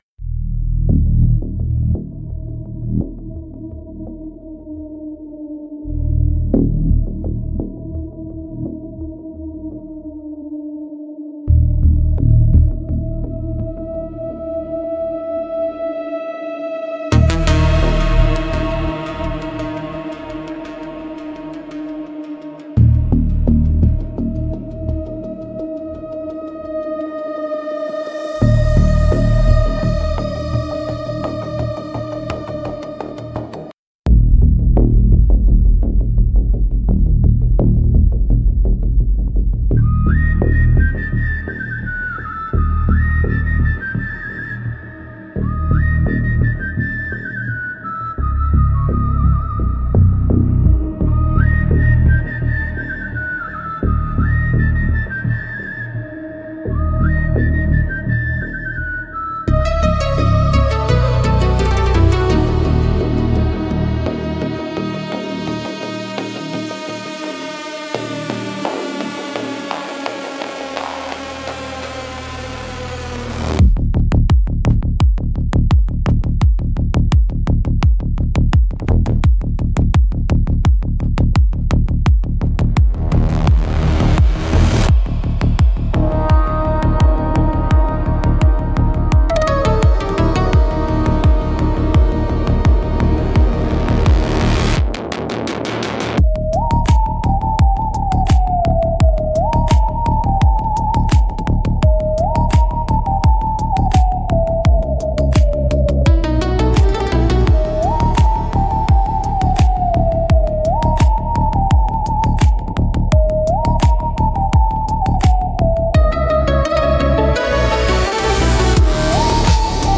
Instrumentals - How the Walls Forget